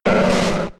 Cri d'Ortide K.O. dans Pokémon X et Y.